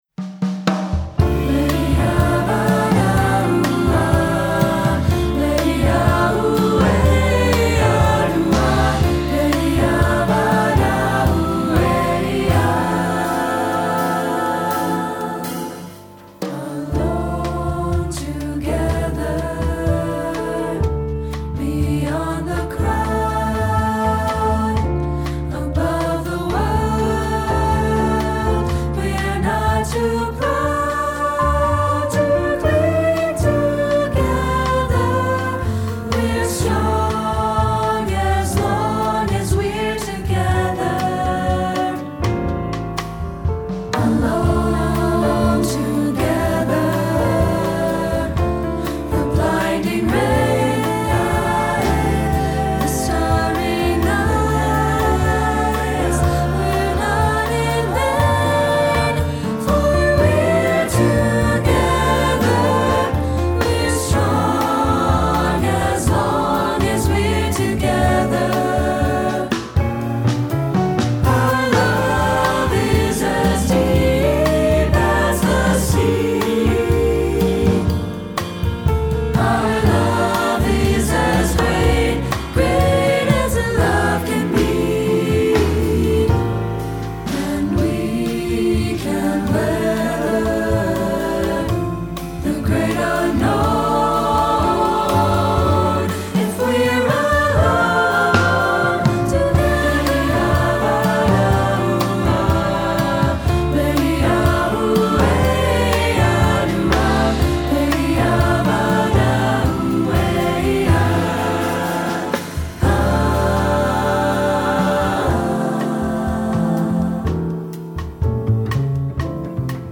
Instrumentation: band or orchestra
jazz, secular
Guitar part:
String bass part:
Drums part: